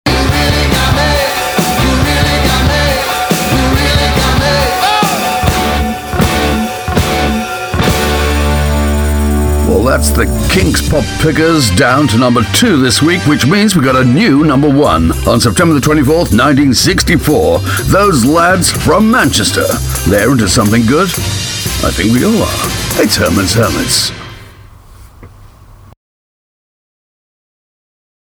Voice Impressions from the past, voices from today.